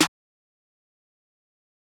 kits/TM88/Snares/SizzSnr2.wav at main
SizzSnr2.wav